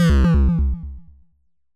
death3.wav